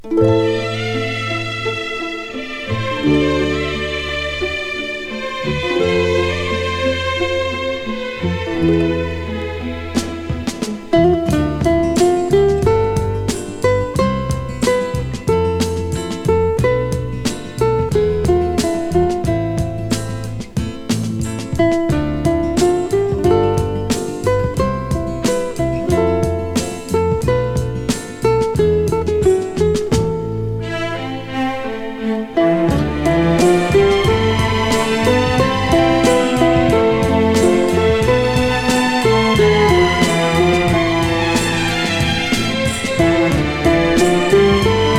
イージーリスニング・スタンダードとも言える楽曲がウクレレとストリングスで彩り豊かに。
Jazz, Pop, Easy Listening　USA　12inchレコード　33rpm　Stereo